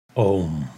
Om.wma